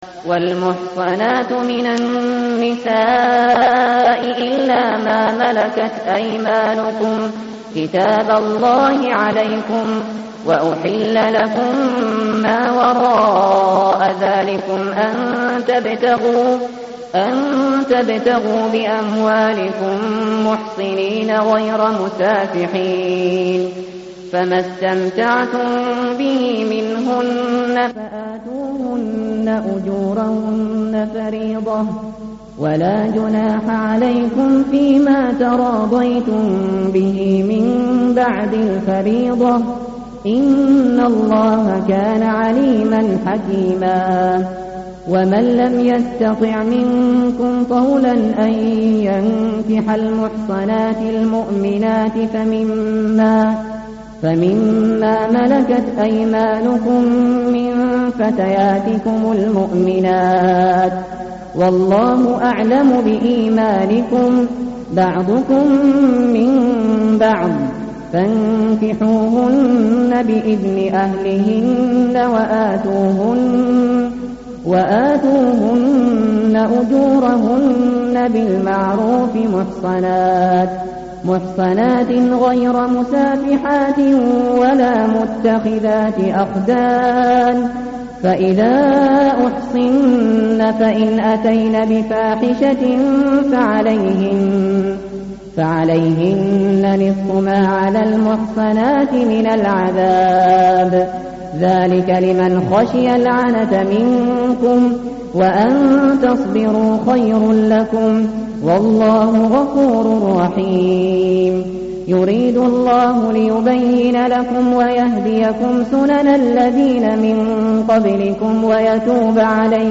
متن قرآن همراه باتلاوت قرآن و ترجمه
tartil_shateri_page_082.mp3